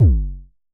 Festival Kicks 07 - F#1.wav